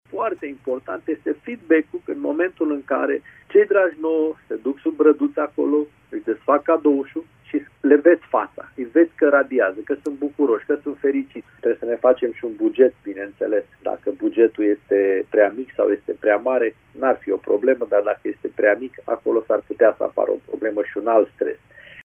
psiholog clinician